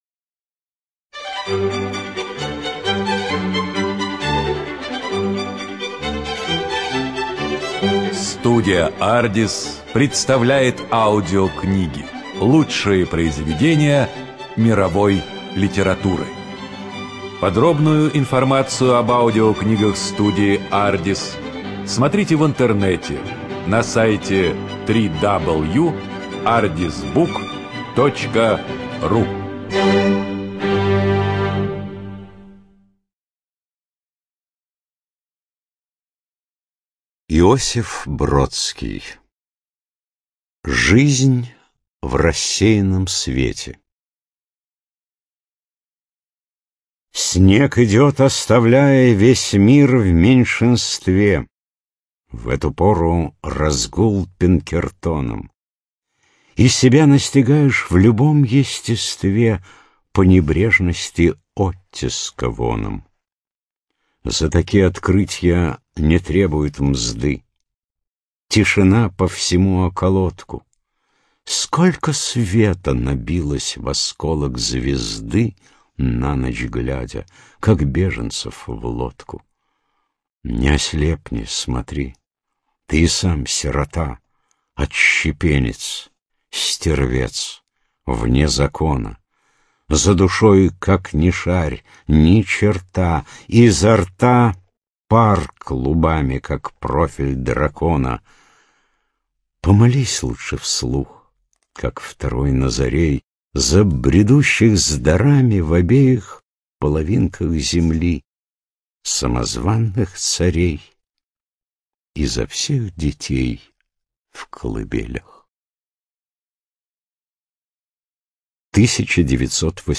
ЖанрПоэзия